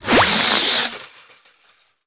Robot Body Movements